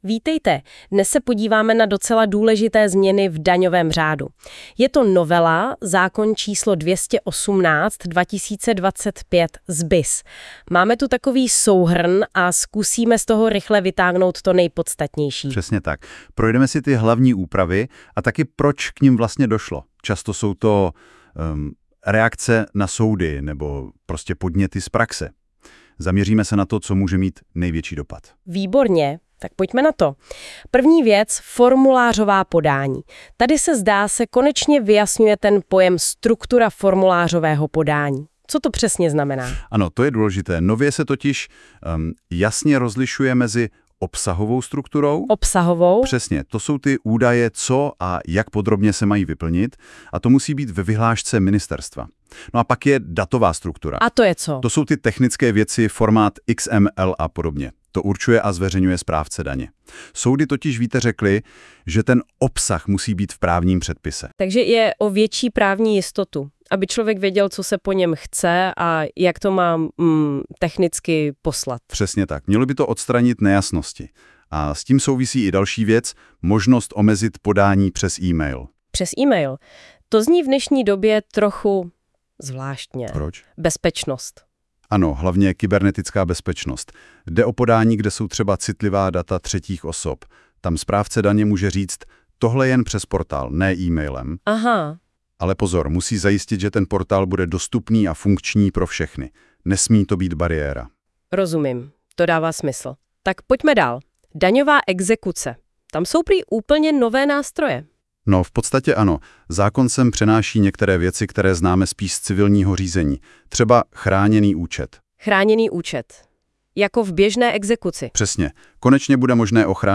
Právní upozornění: podcasty s informačními souhrny nejdůležitějších změn byly vytvořeny nástrojem NotebookLM společnosti Google a na těchto stránkách běží ve zkušebním provozu.